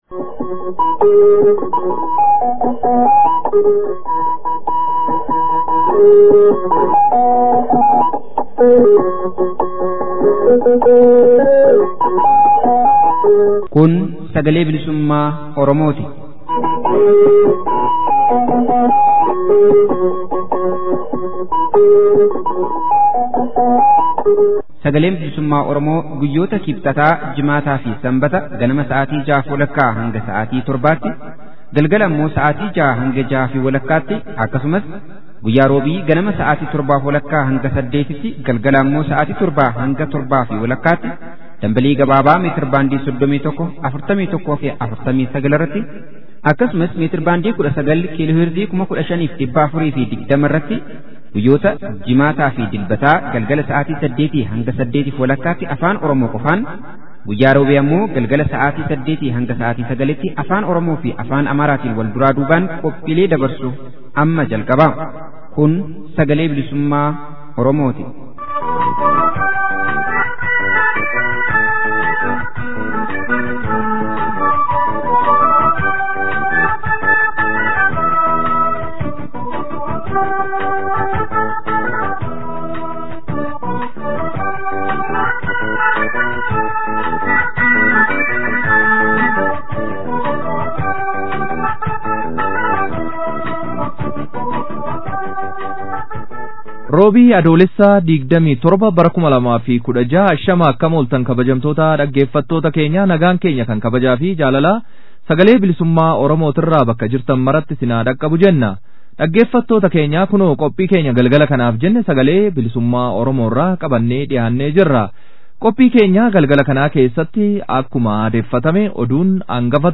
SBO Adoolessa 27,2016. Oduu, Warraaqsa FXG Lixa Shaggar keessatti deemaa jiru irratti gaaffii fi deebii dargaggoota Oromoo waliin geggeeffamee fi qophii haala yeroo irratti hundaawe, akkasumas SBO Sagantaa Afaan Amaaraa